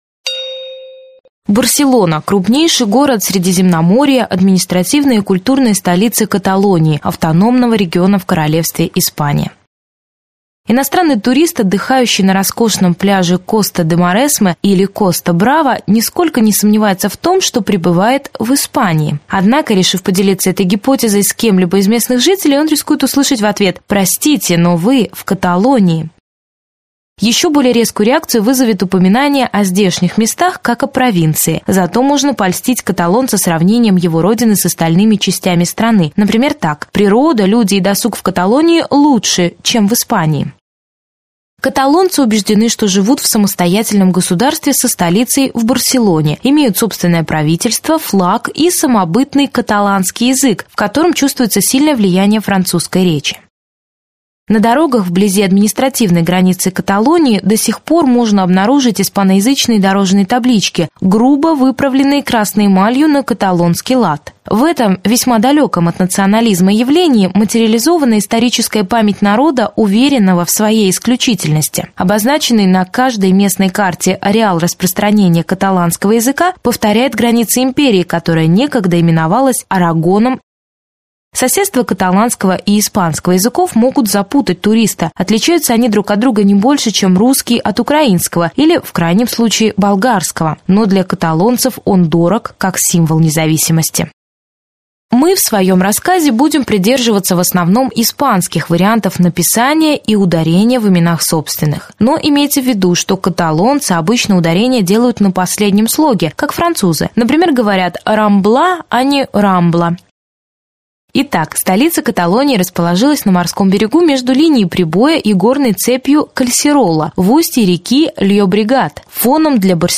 Аудиокнига Барселона: Рамбла и Готический квартал. Аудиогид | Библиотека аудиокниг
Прослушать и бесплатно скачать фрагмент аудиокниги